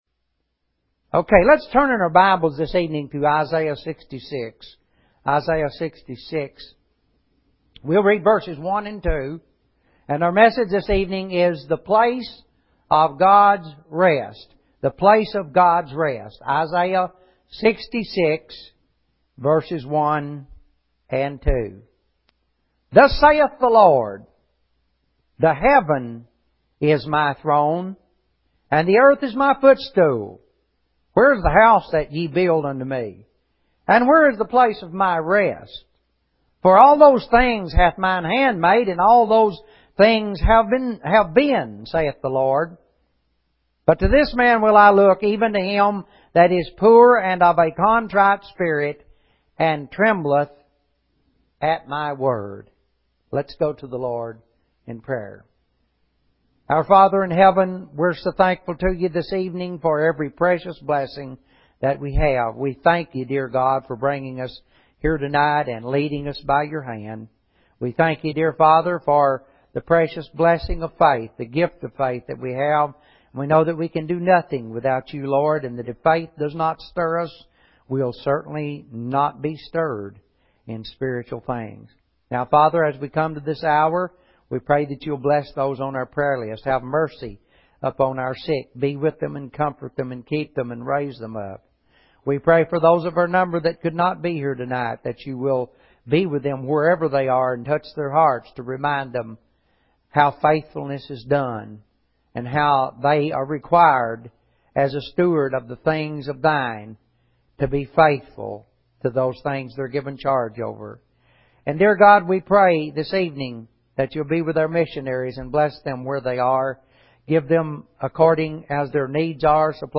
Clicking on an item will open an audio sermon on the subject.